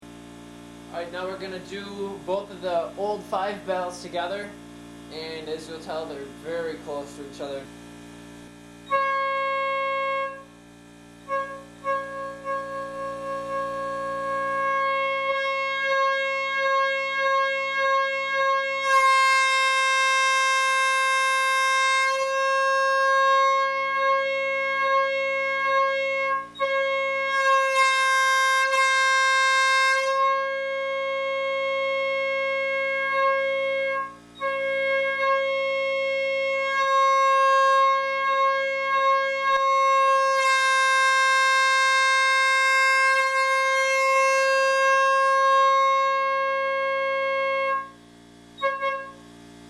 Comparison of both 5 bells at the same time.
P5A_TESTS_5Bells.mp3